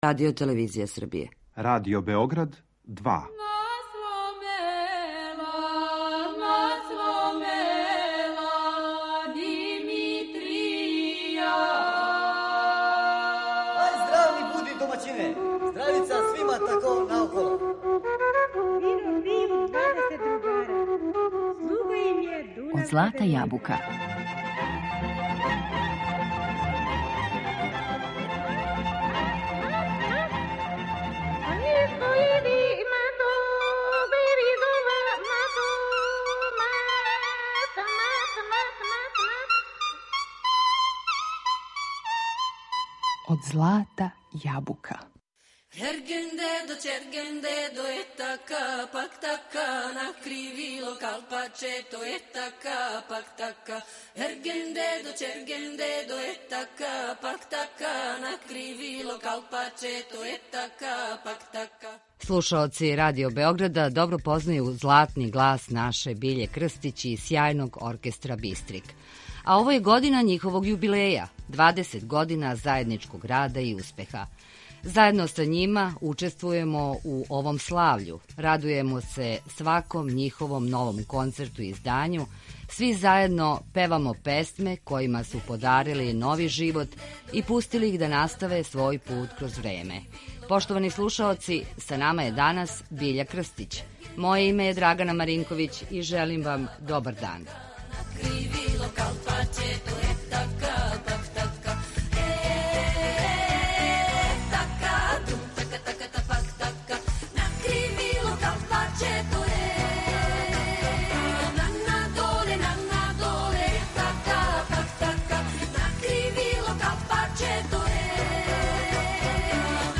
Гост данашњег издања емисије Од злата јабука је Биља Крстић.